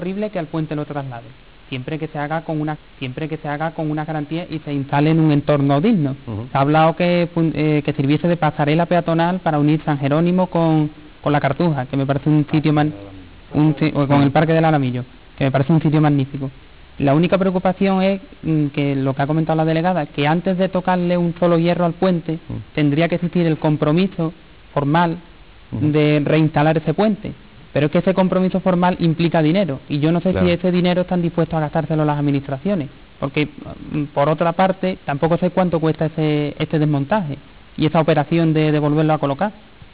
A finales de 1997 se organizó un debate televisivo en Onda Giralda Televisión, en el cual se mostraron las diferentes posturas existentes sobre el Puente de Alfonso XIII.
- Félix Manuel Pérez Miyares, Presidentte del Puerto de Sevilla